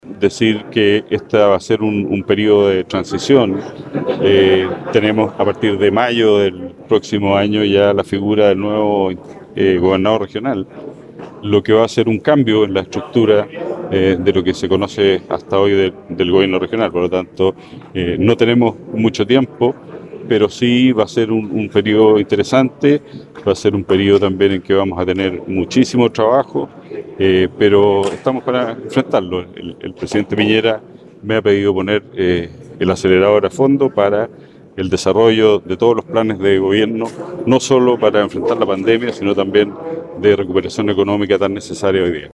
Por ello, durante la ceremonia de traspaso de mando -realizada en el salón mural Gregorio de la Fuente de la intendencia- agradeció a sus compañeros del CORE y manifestó su interés de continuar el trabajo conjunto para lograr que las decisiones «vayan en directo beneficio de las personas».